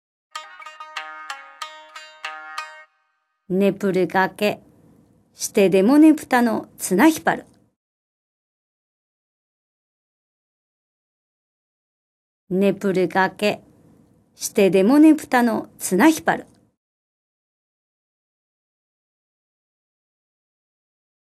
本場のお国言葉を読み上げる方言かるたシリーズ第二弾「津軽弁かるた」が新登場！